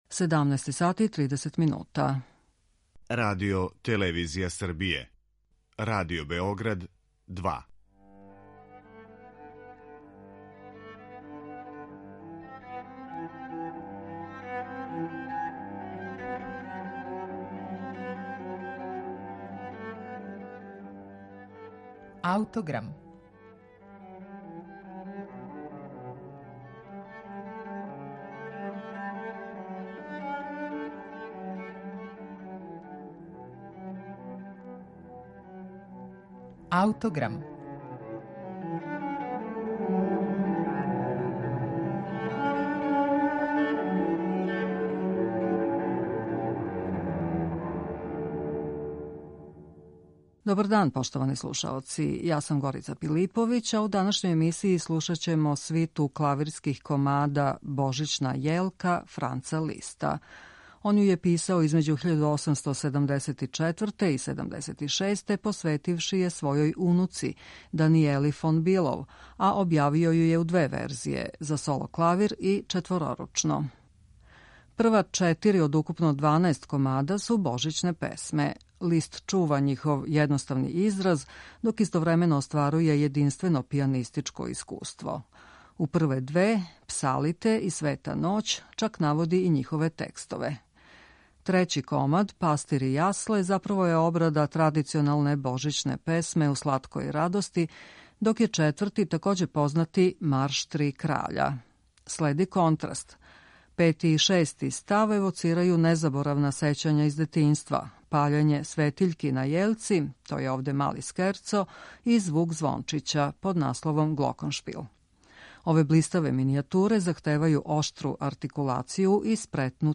Необична клавирска музика Франца Листа
свиту клавирских комада